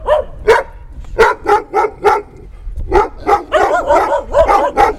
Index of /cianscape/birddataDeverinetal2025/File_origin/Noise-ESC-50/dog